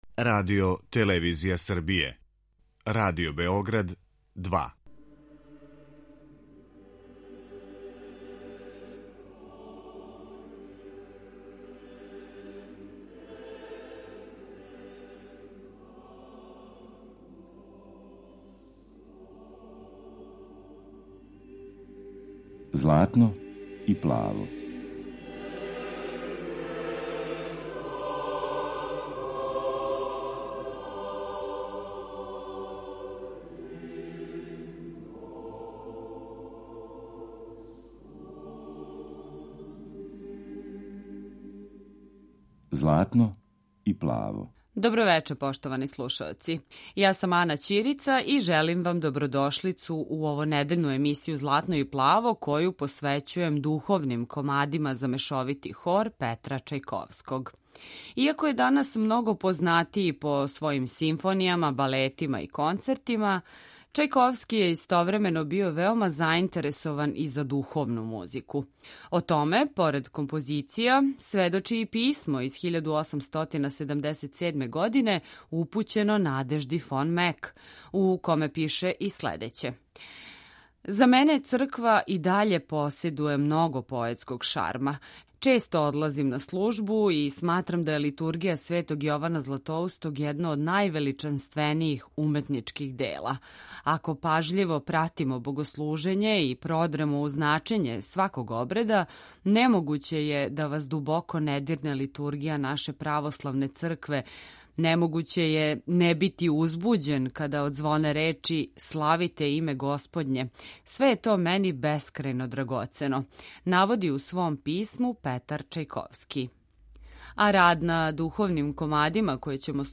Емисија посвећена православној духовној музици.